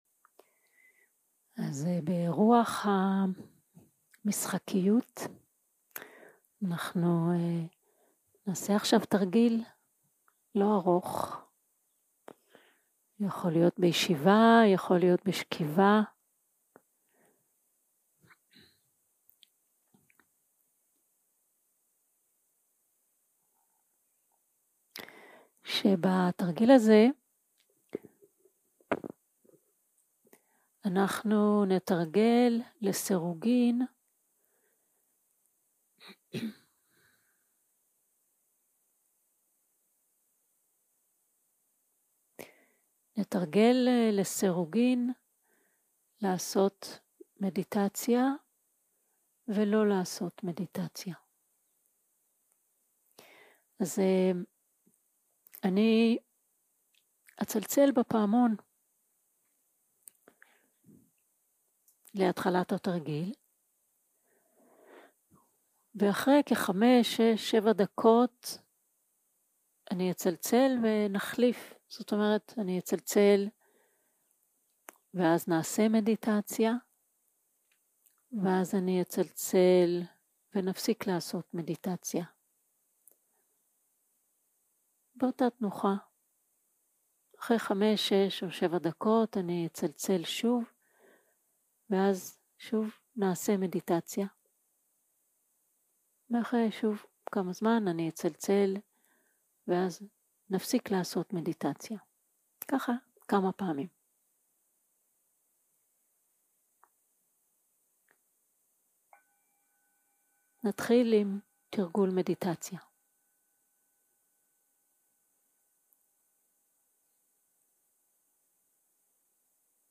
יום 4 - הקלטה 9 - צהרים - מדיטציה מונחית
סוג ההקלטה: מדיטציה מונחית